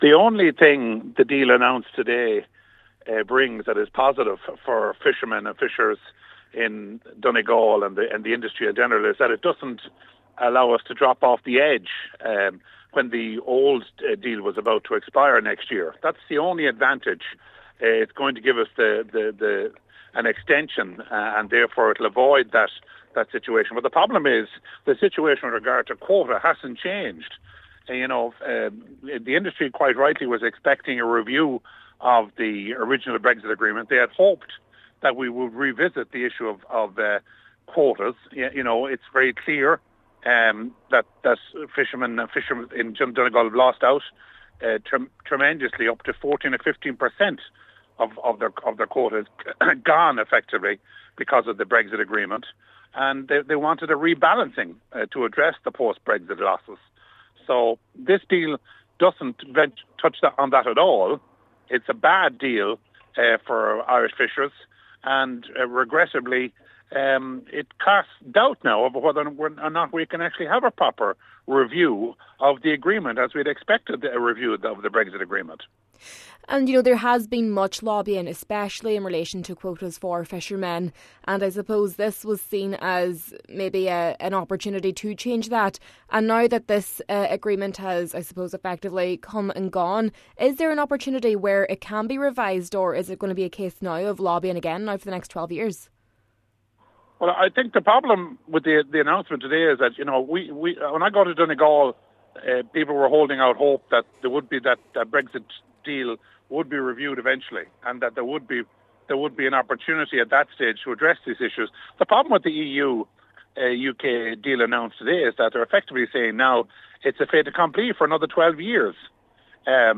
Midlands Northwest MEP Ciaran Mullooly says the Irish Minister going to the Council of Europe to veto the deal on the issue of fishing quotas: